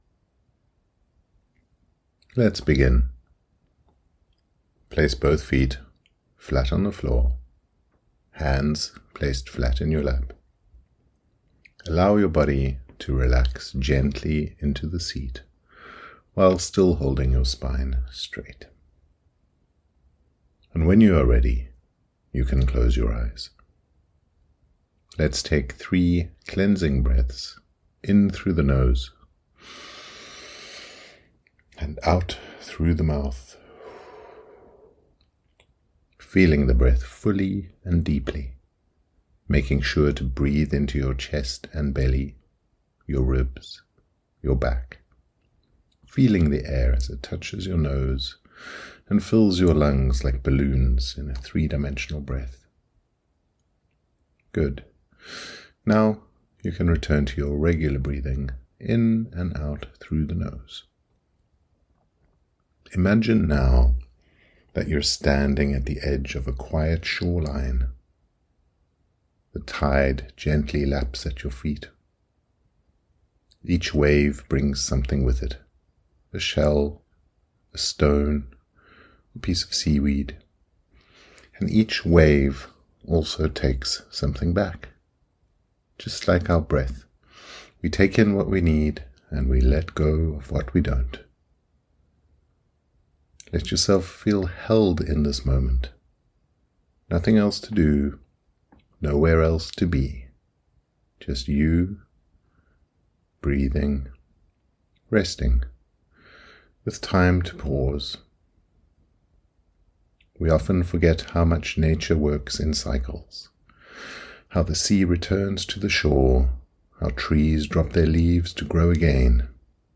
Cap Kraken Meditation
SC04-meditation.mp3